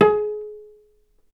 healing-soundscapes/Sound Banks/HSS_OP_Pack/Strings/cello/pizz/vc_pz-G#4-ff.AIF at ae2f2fe41e2fc4dd57af0702df0fa403f34382e7
vc_pz-G#4-ff.AIF